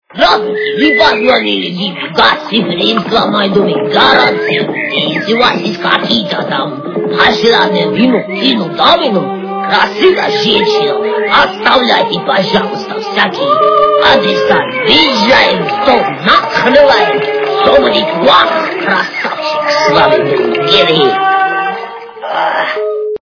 При прослушивании Автоответчик - Гостеприимный Гиви качество понижено и присутствуют гудки.